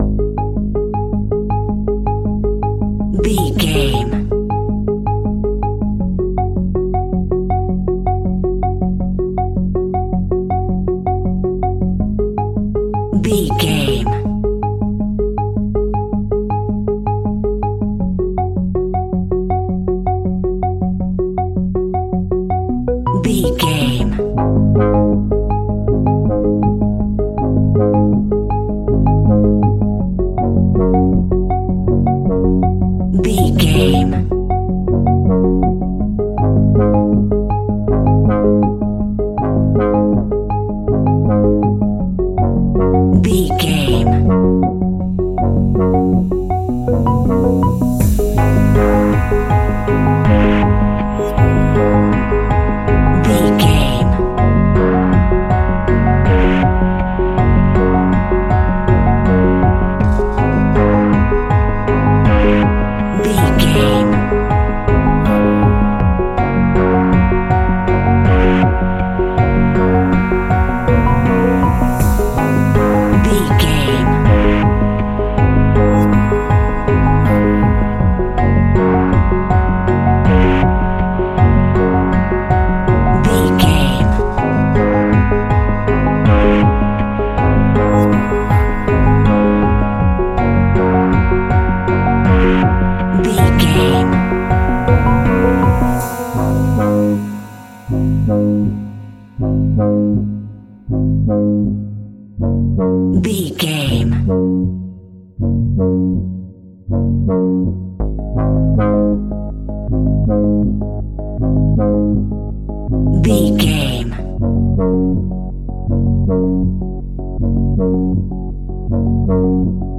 Aeolian/Minor
G#
Slow
ominous
dark
haunting
eerie
piano
synthesiser
drums
instrumentals
horror music